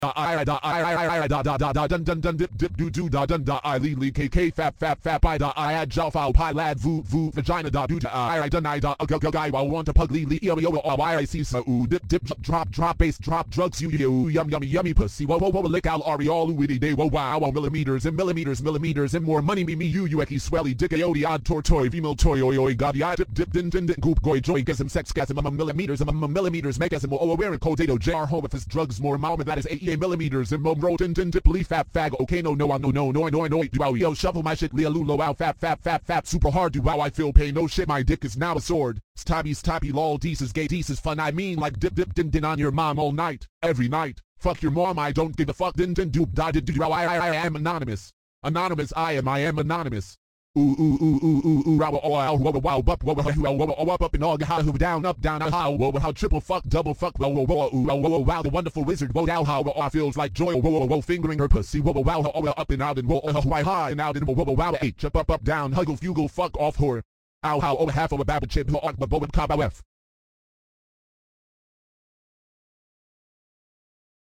Real crazy fail beatboxer